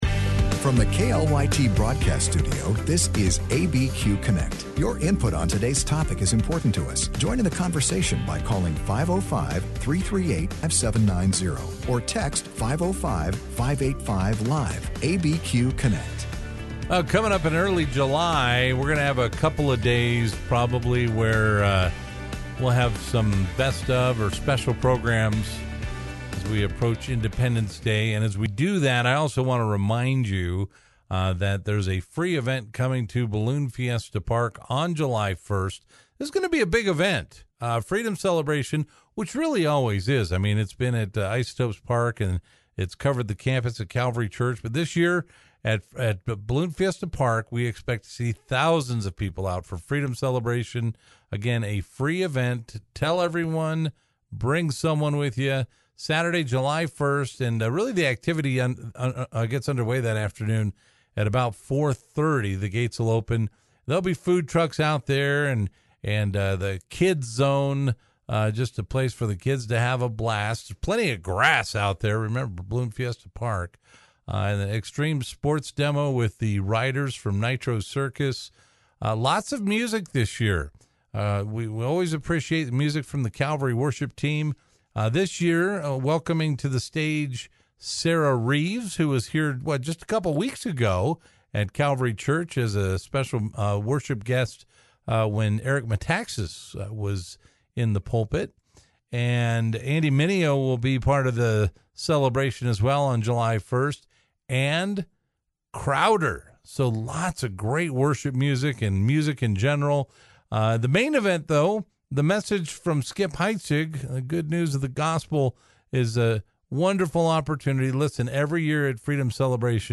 Albuquerque's live and local call-in show.